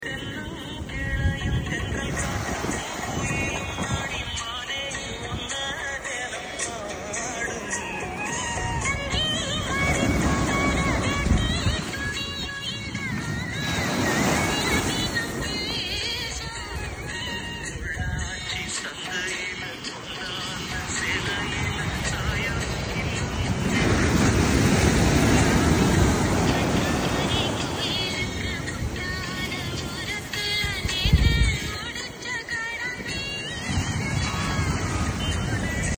2AM, the world asleep — just me, the waves, my tea, and my playlist.🎧🌊🫚☕